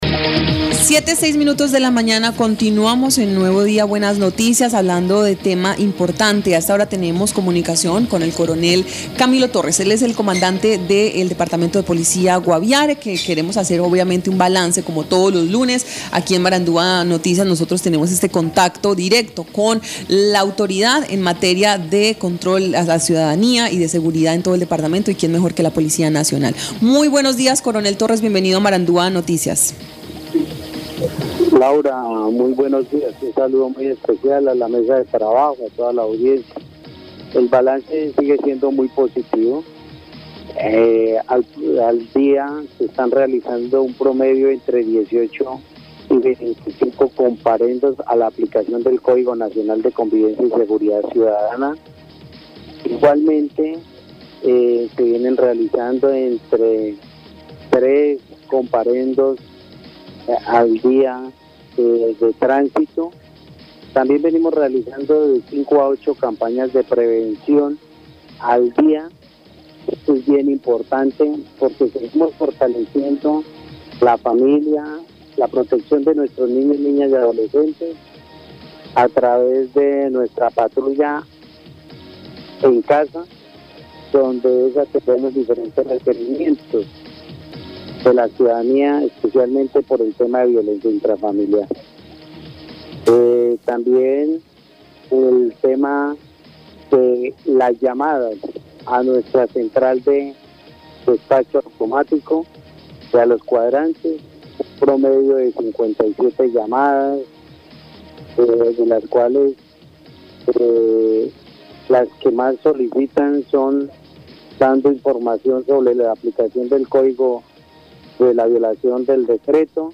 Escuche al Coronel Camilo Torres, comandante de Policía Guaviare.